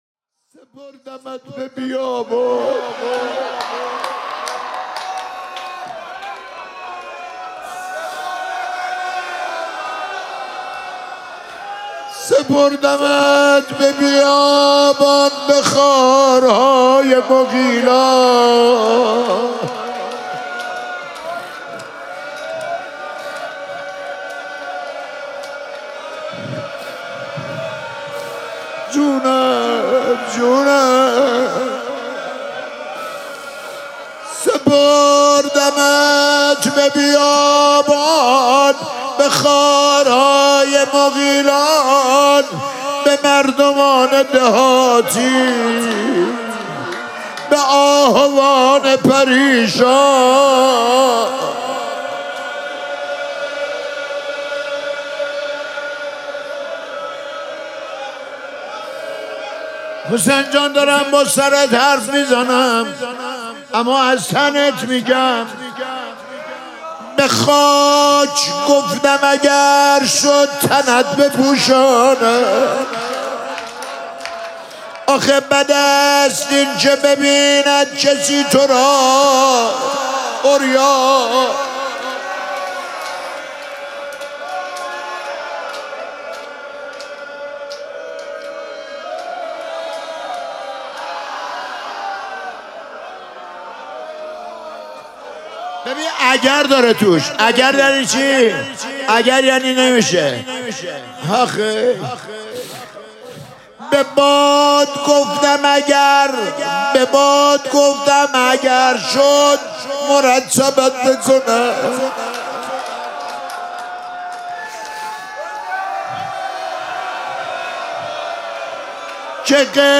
مراسم عزاداری شام شهادت حضرت زینب سلام‌الله‌علیها
حسینیه ریحانه الحسین سلام الله علیها
روضه